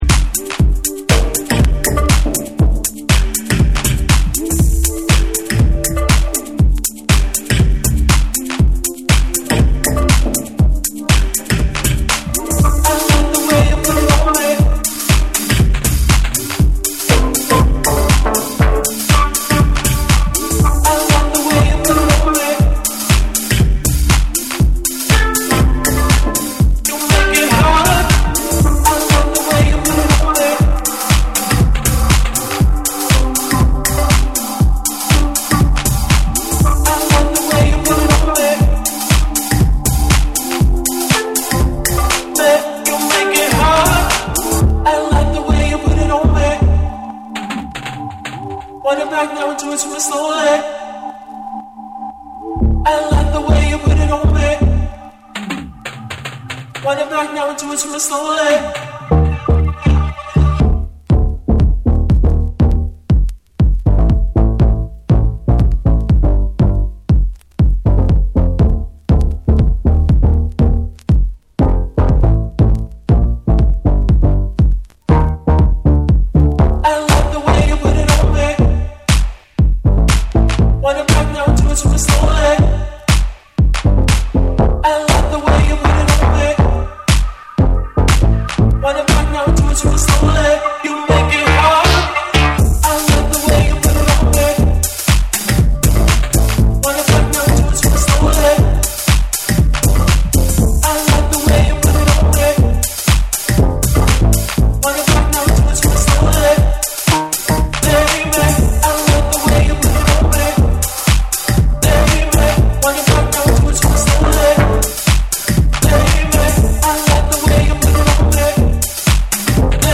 中毒性のあるヴォーカルサンプルを使ったエレクトリックなダンス・トラックを展開する2。
TECHNO & HOUSE